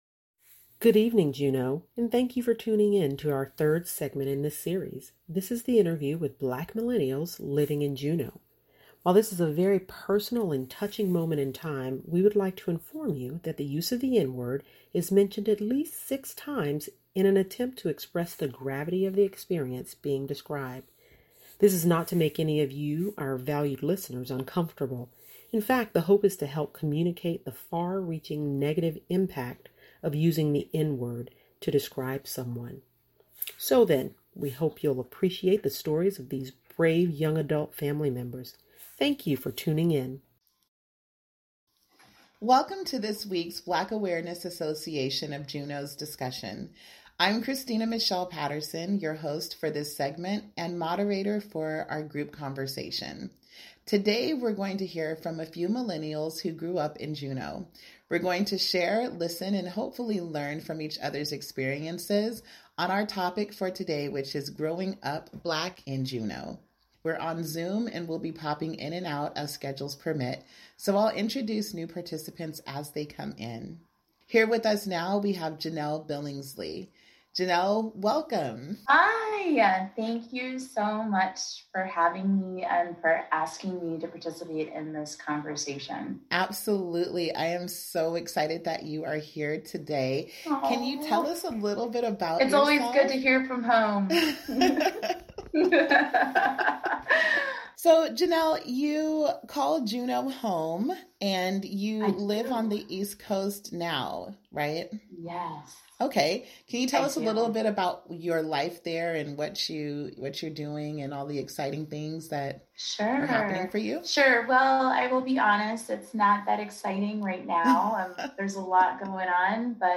On today’s show, we will continue our month-long series featuring conversations with the Juneau Black Awareness Association Thursdays on Juneau Afternoon.